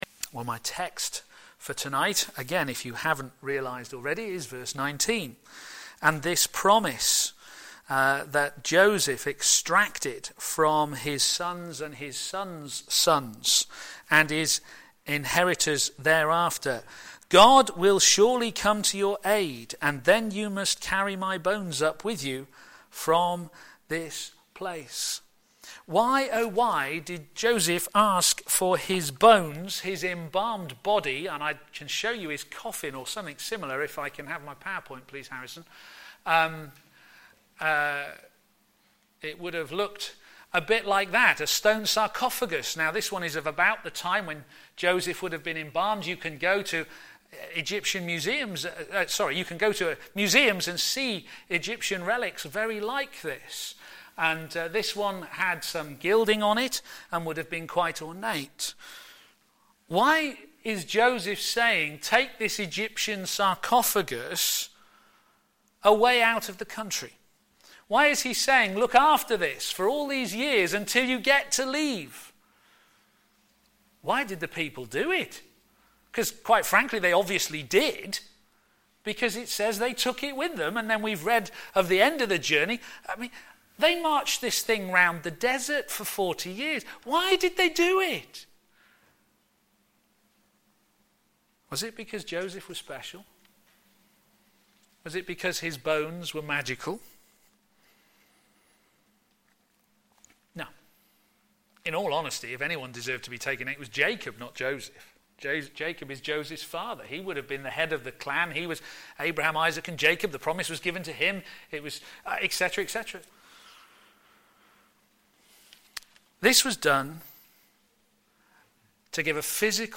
Media Library Media for p.m. Service on Sun 21st Sep 2014 18:30 Speaker
Theme: To Follow His lead Sermon In the search box below, you can search for recordings of past sermons.